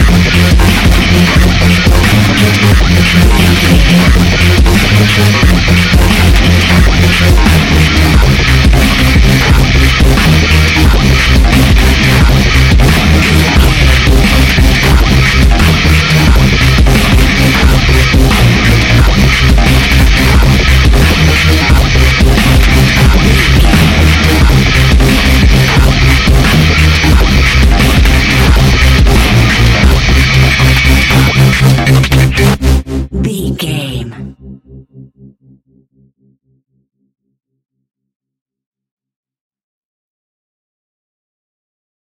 royalty free music
Epic / Action
Fast paced
Aeolian/Minor
aggressive
dark
intense
synthesiser
drum machine
breakbeat
energetic
synth leads
synth bass